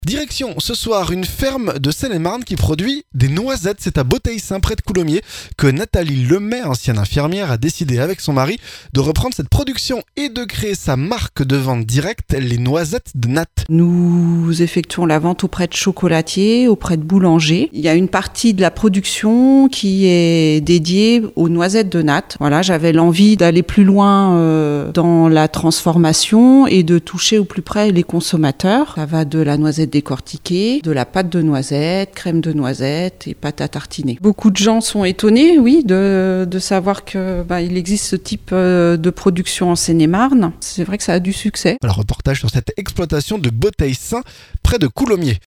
REPORTAGE - Sur une exploitation de production de noisettes en Seine-et-Marne!